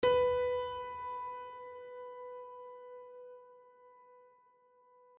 multiplayerpiano / sounds / LoudAndProudPiano / b3.mp3
b3.mp3